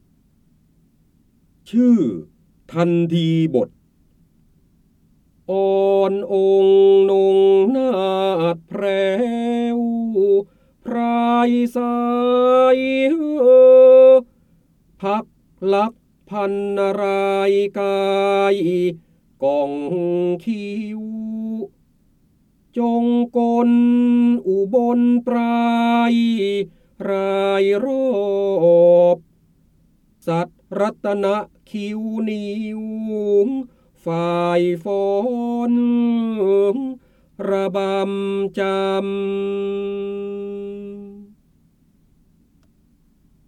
เสียงบรรยายจากหนังสือ จินดามณี (พระโหราธิบดี) ชื่อทัณฑีบท
คำสำคัญ : พระเจ้าบรมโกศ, ร้อยแก้ว, ร้อยกรอง, พระโหราธิบดี, การอ่านออกเสียง, จินดามณี